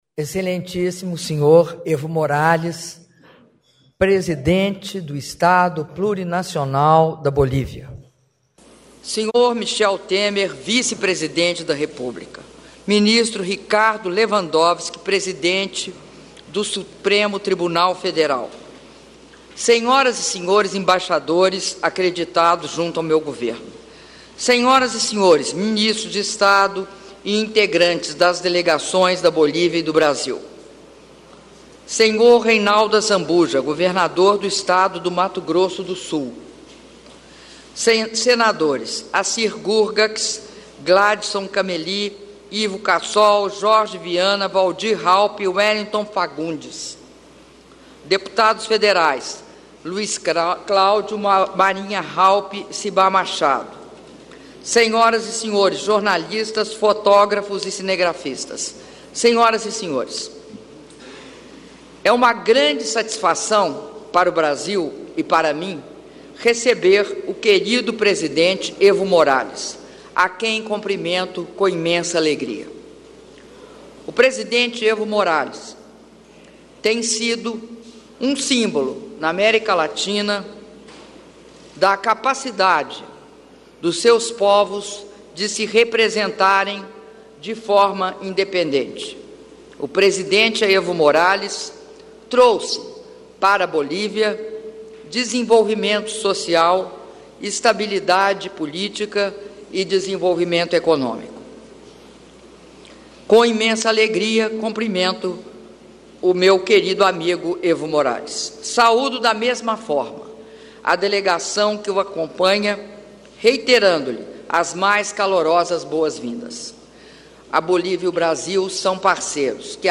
Áudio do brinde da Presidenta da República, Dilma Rousseff, durante o Almoço em homenagem ao presidente do Estado Plurinacional da Bolívia, Evo Morales - Brasília/DF- (6min42s)